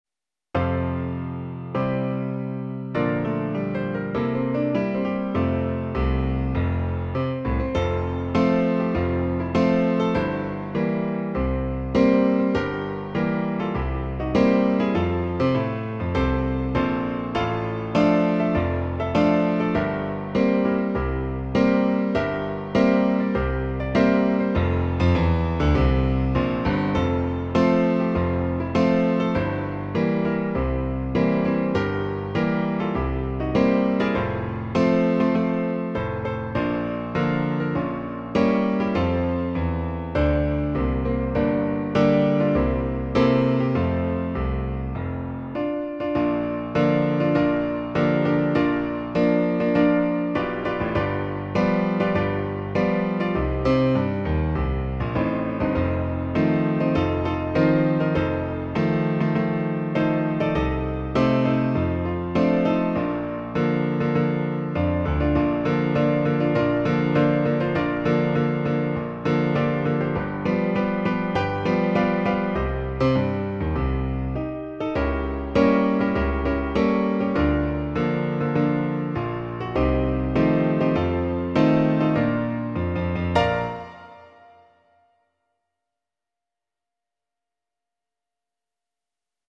HinoProfessorandos-1923.mp3 - execução em computador